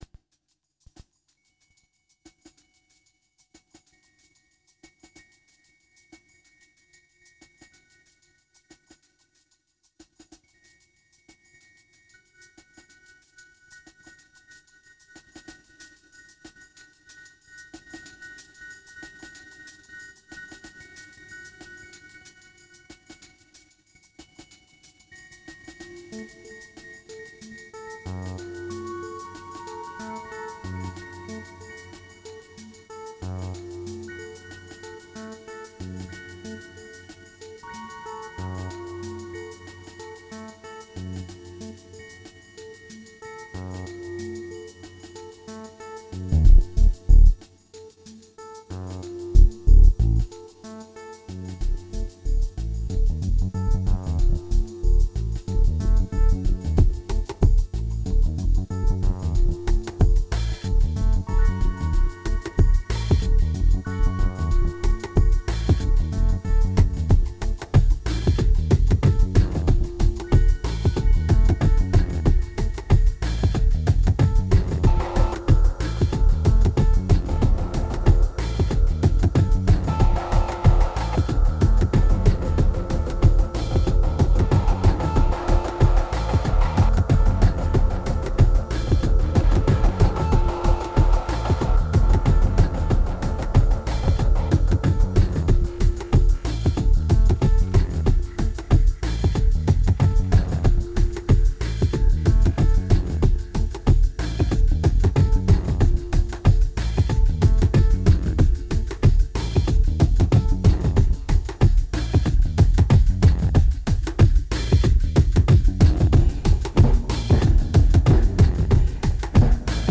ambient album
2260📈 - -2%🤔 - 93BPM🔊 - 2010-11-11📅 - -326🌟